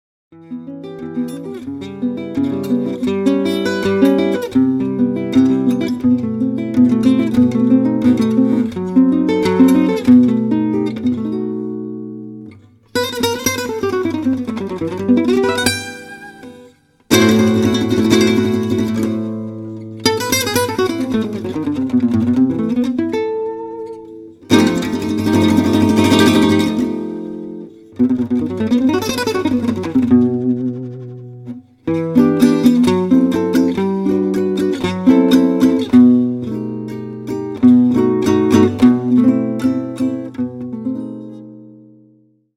Plans d’impro dans le plus pur style Django.